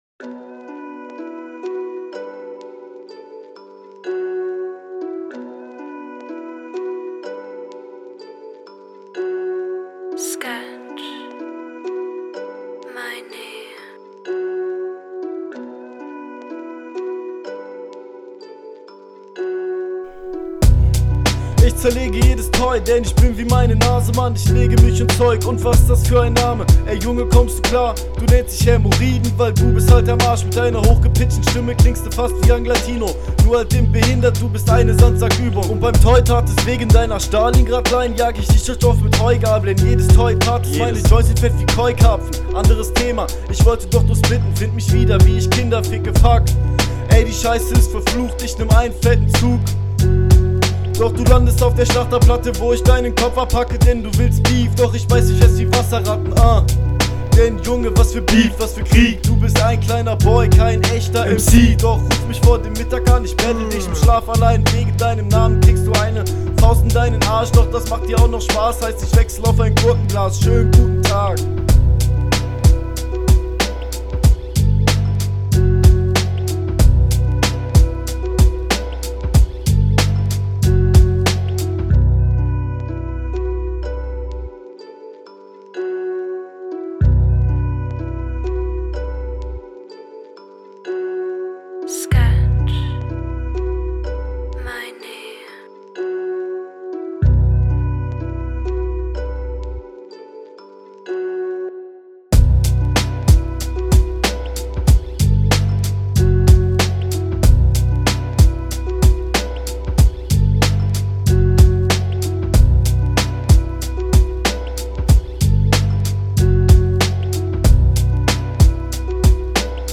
Du kommst auch sehr gut auf dem Beat klar.